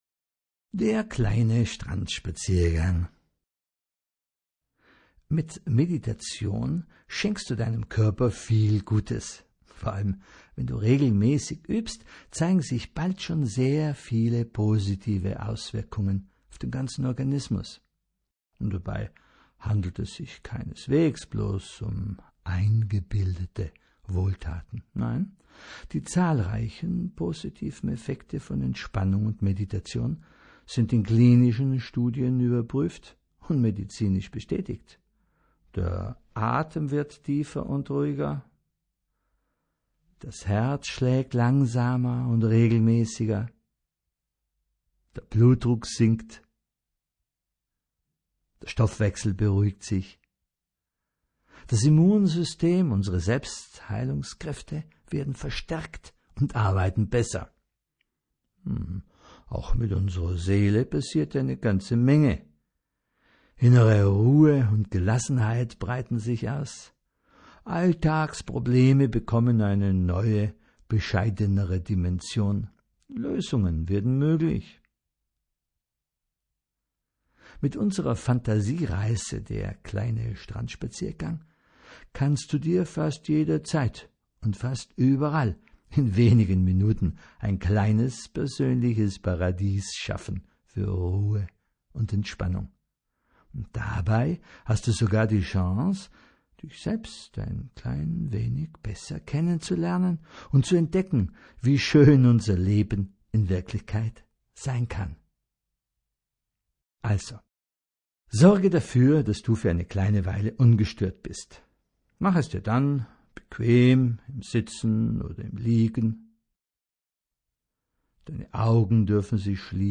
Strandspaziergang gerade jetzt: Einfach locker über den Strand spazieren …